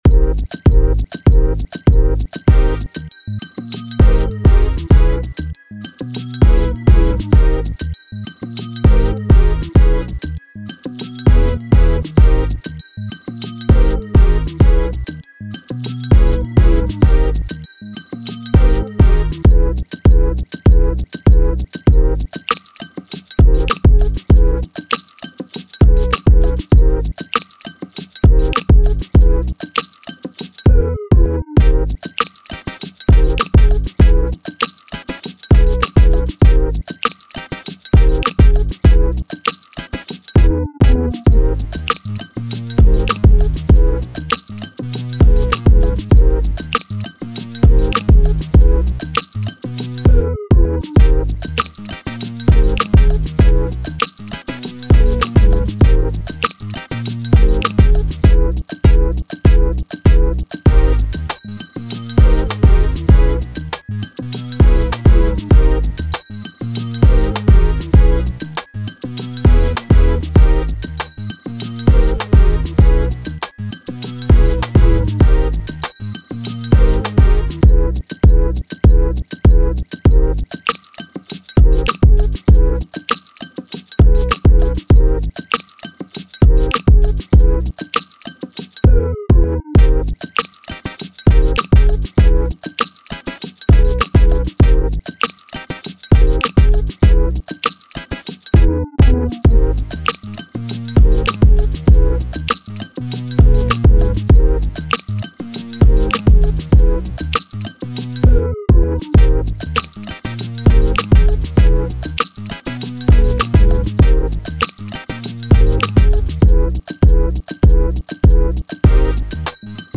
Hip Hop, R&B
Ab Minor